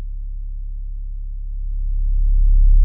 MB 808 (24).wav